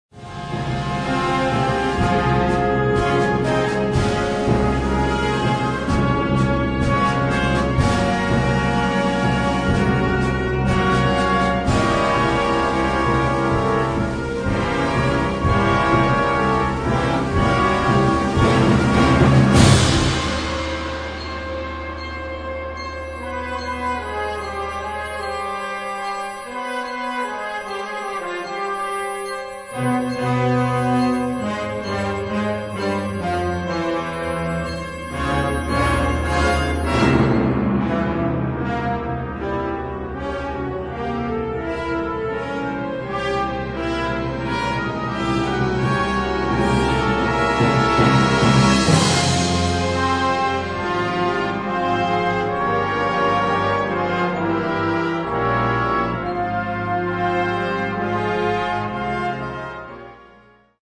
Partitions pour brass band.